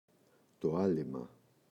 άλειμμα, το [Ꞌalima] – ΔΠΗ
άλειμμα, το [‘alima]: (μτφ.) χοιρινό λίπος με το οποίο αλείφουμε.